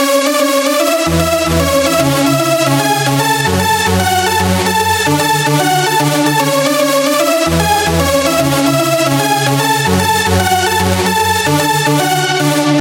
标签： 150 bpm Hardstyle Loops Synth Loops 2.16 MB wav Key : A
声道立体声